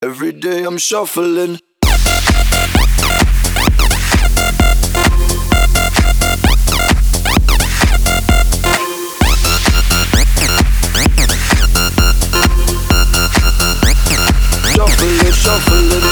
I cut the clip from my album online for a 16 second loop.